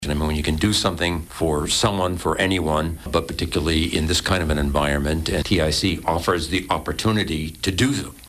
in studio for the live event